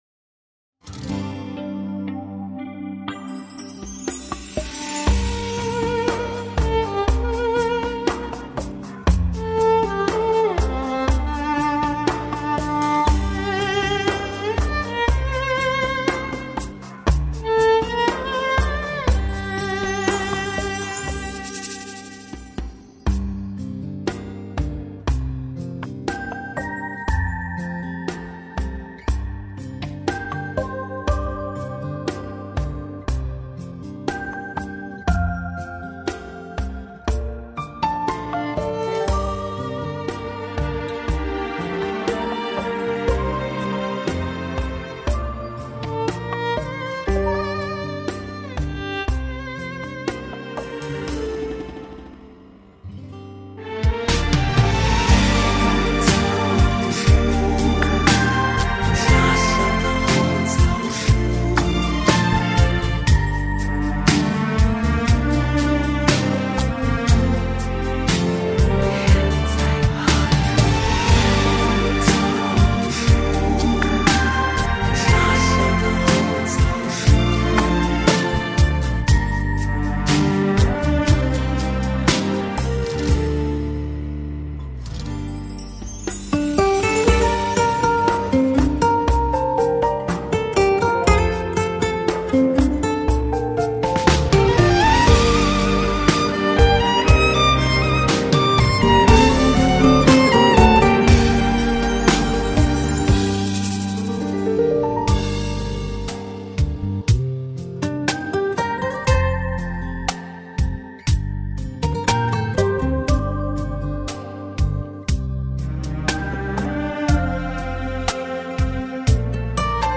无 调式 : 降B 曲类